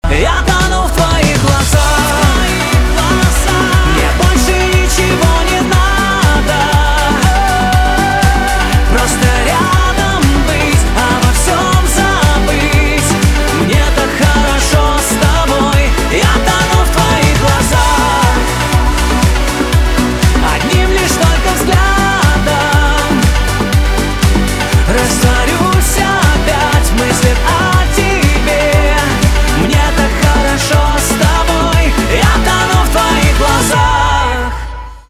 поп
романтические , чувственные , красивые